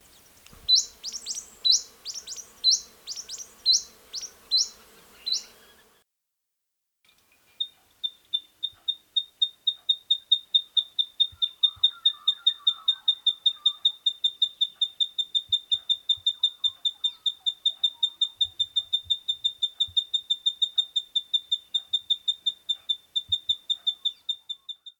The Eastern Spinebill is one of the commonest and most colourful honeyeaters of this district.
It is also noisy, not only vocally (click on audio below) but with its clip-clop sounding wing-beats as well.
eastern-spinebill.mp3